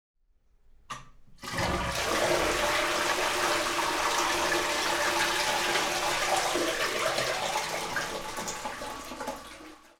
You are here: Home » Kunst & Forschung » Akustik » Musikalische Akustik » Klänge, die die Welt bedeuten » Klänge » Toilette 2
Toilette 2
toilette2